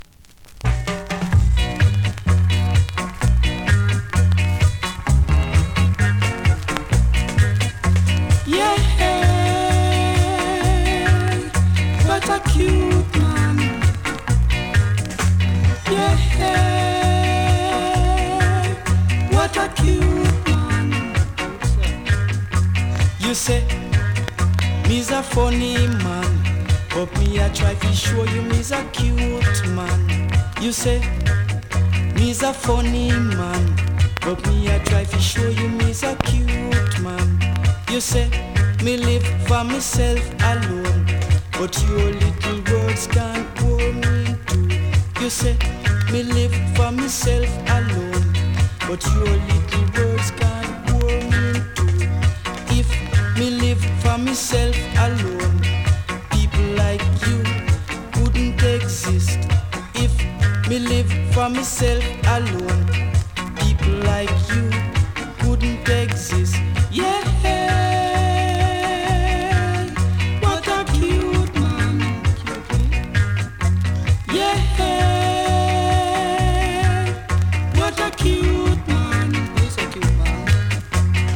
スリキズ、ノイズそこそこありますが